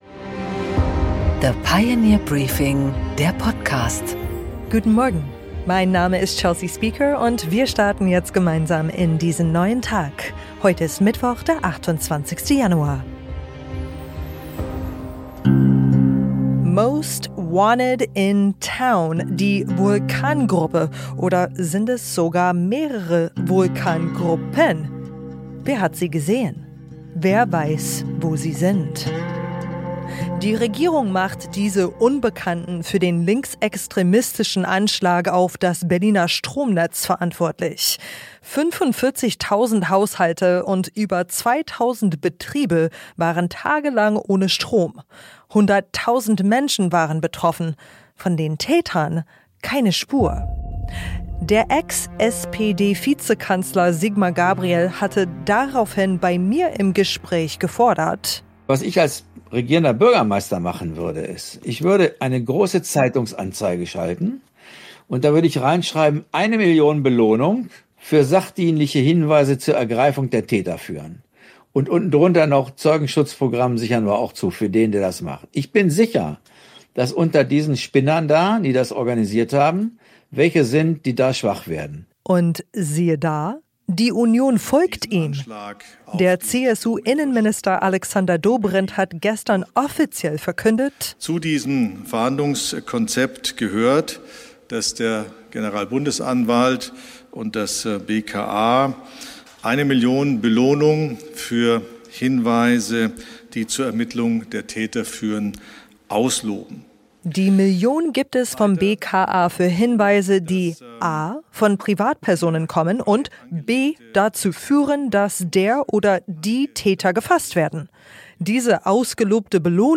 Im Gespräch: Ola Källenius, CEO Mercedes-Benz Group, spricht mit Gabor Steingart auf der Pioneer One über die deutsche Wirtschaft und die Autobranche.